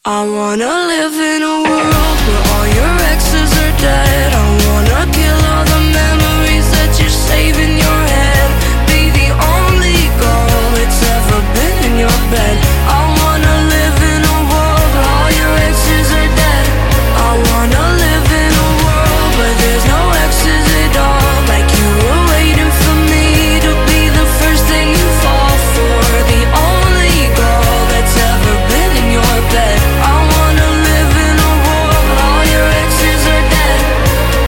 • Качество: 128, Stereo
рок